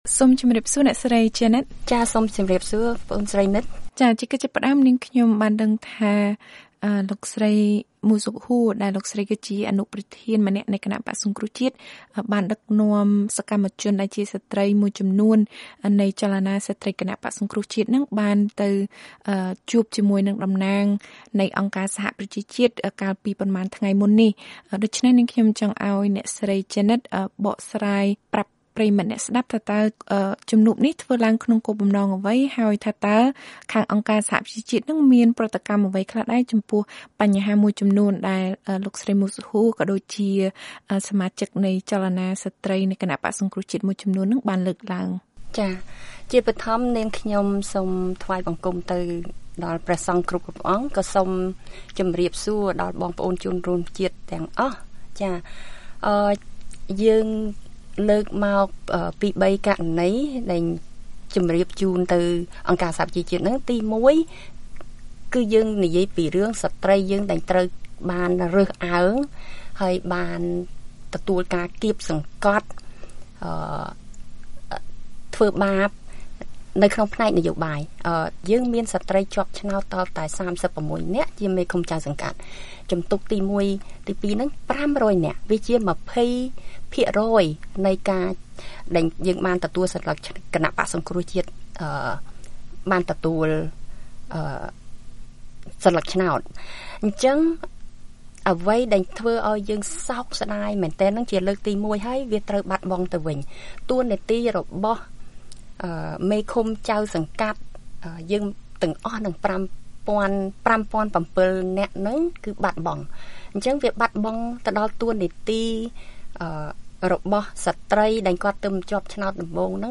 បទសម្ភាសន៍ VOA៖ សកម្មជនស្ត្រីបក្សប្រឆាំងសុំការអន្តរាគមន៍ពីអង្គការសហប្រជាជាតិពាក់ព័ន្ធនឹងការគាបសង្កត់លើសកម្មជនស្ត្រីបក្សនៅកម្ពុជា